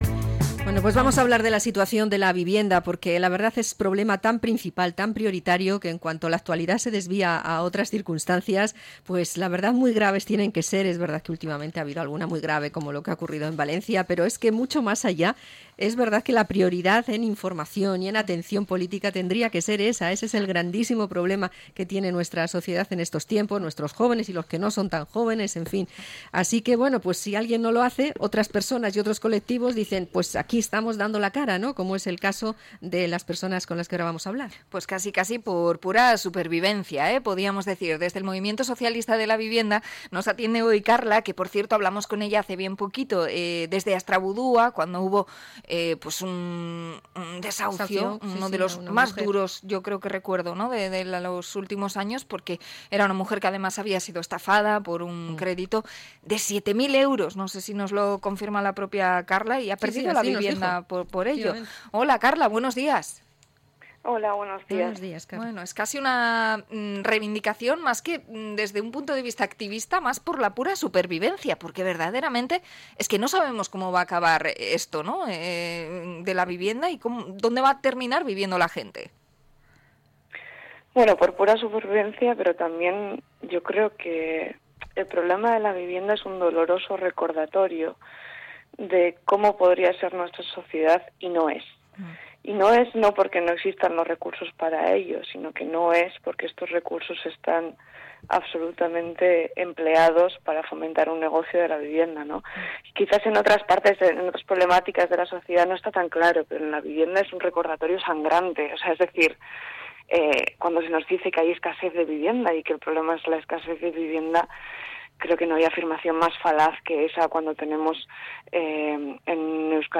Entrevista al Sindicato Socialista de Vivienda